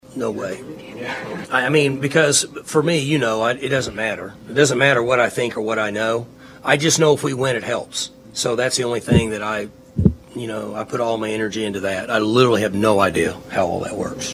Oklahoma State head football coach Mike Gundy talked to the media on Monday following a head-scratching loss to UCF last weekend.